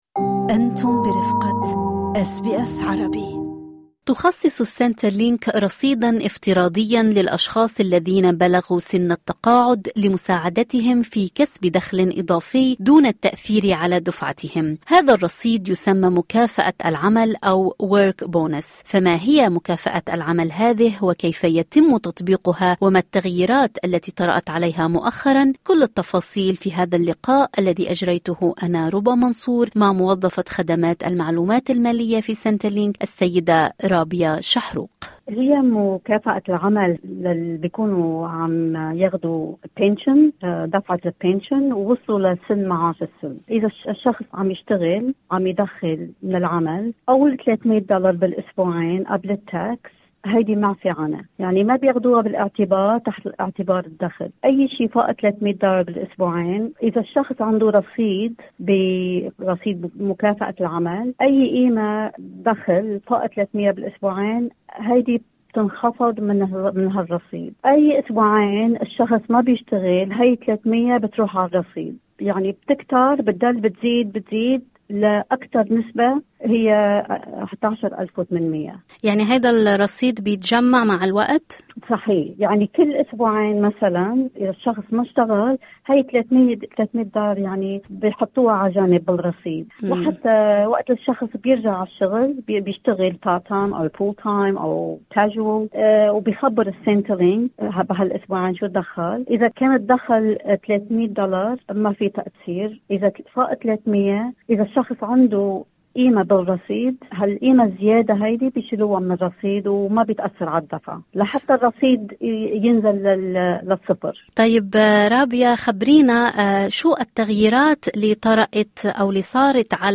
اضغطوا على الملف الصوتي المرفق أعلى الصفحة لتتعرفوا على كل الإجابات في اللقاء